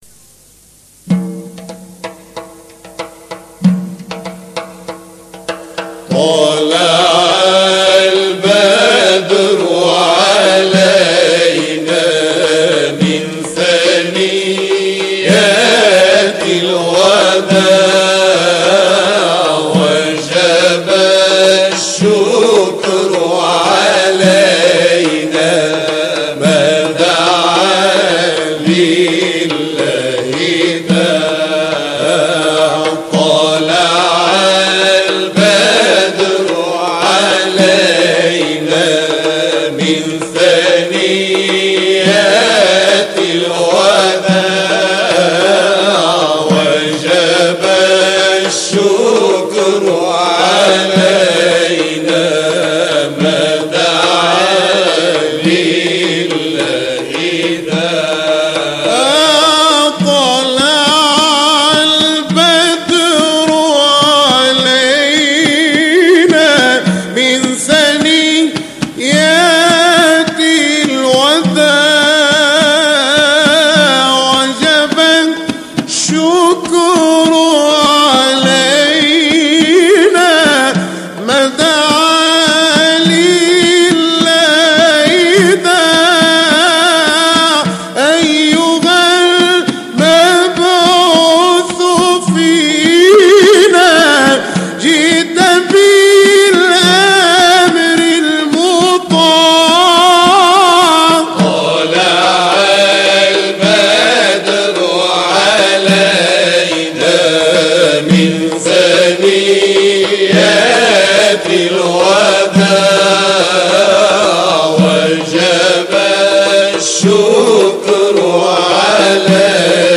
مقام سيكا ( تواشيح ) ـ طلع البدر علينا - لحفظ الملف في مجلد خاص اضغط بالزر الأيمن هنا ثم اختر (حفظ الهدف باسم - Save Target As) واختر المكان المناسب